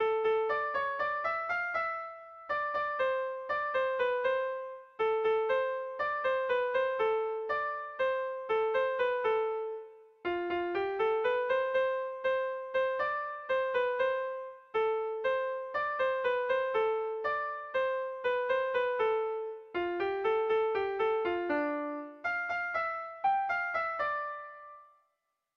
Bertso melodies - View details   To know more about this section
Kontakizunezkoa
Hamarrekoa, txikiaren moldekoa, 5 puntuz (hg) / Bost puntukoa, txikiaren moldekoa (ip)
ABDBE